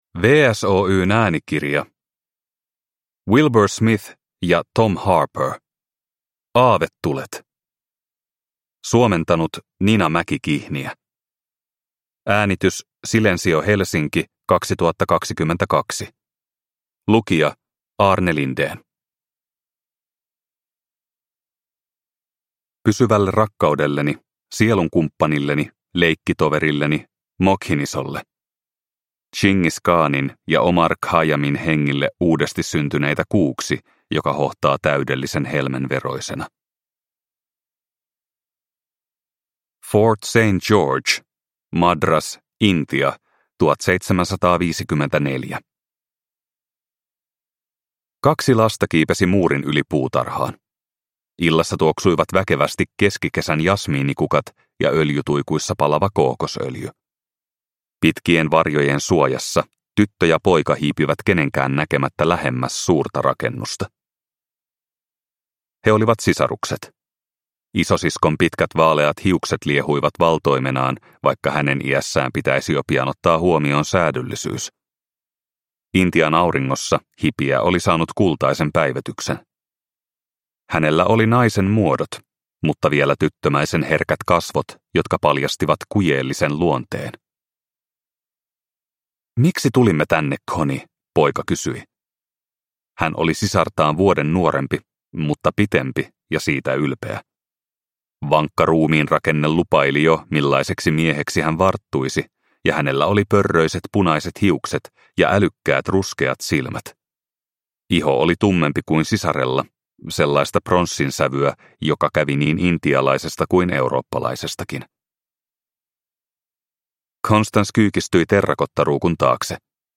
Aavetulet – Ljudbok – Laddas ner